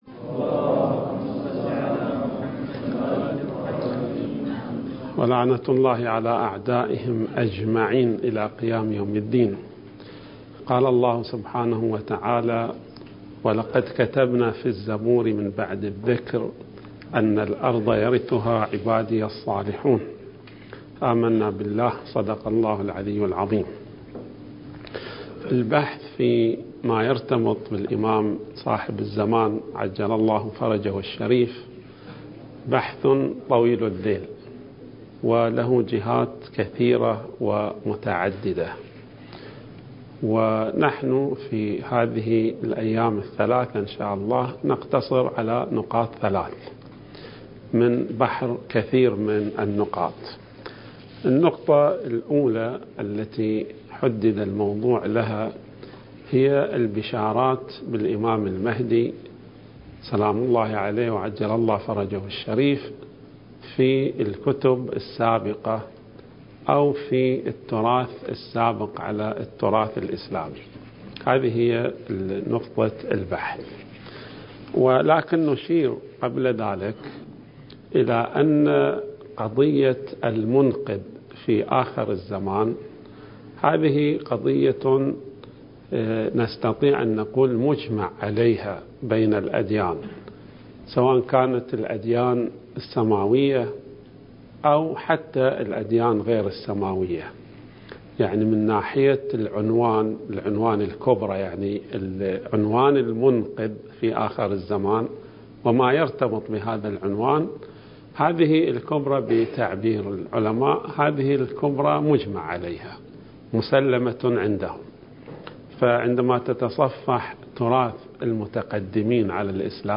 الدورة المهدوية الأولى المكثفة (المحاضرة الثالثة والعشرون)
المكان: النجف الأشرف